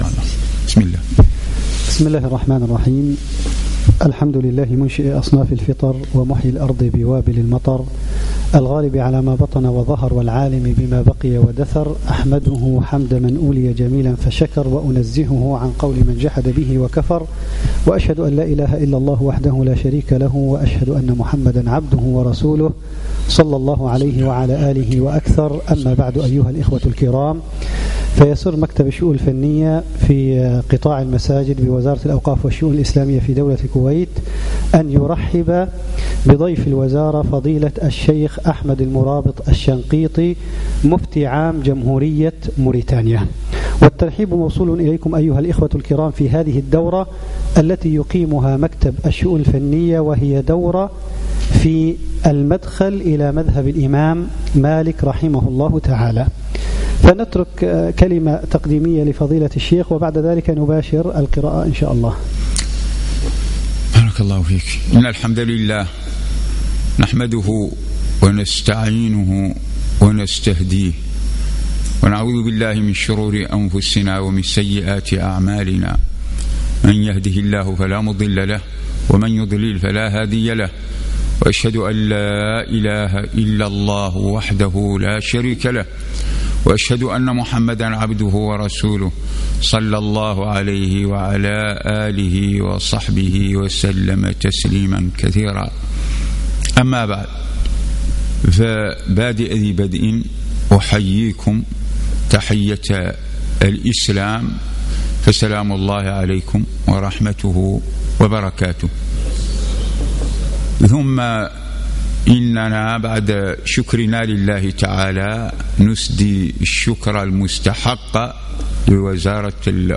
الدرس الأول